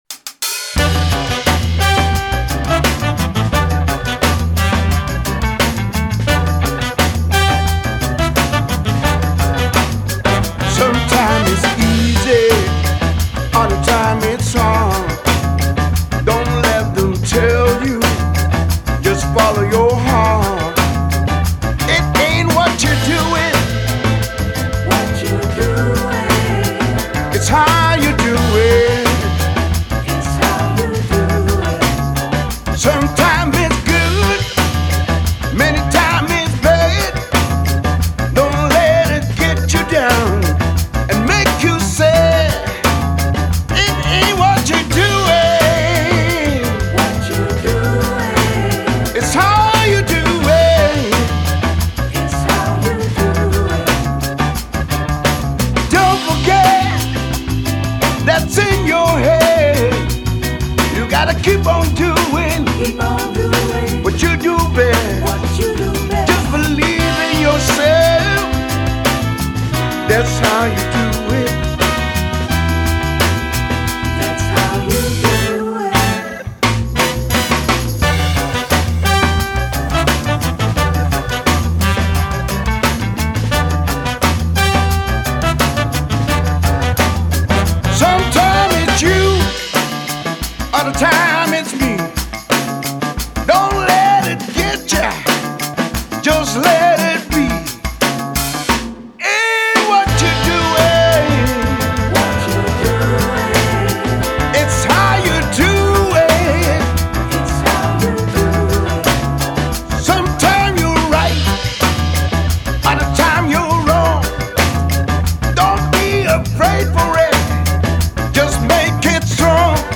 Raw and gritty funk, just the way we like it.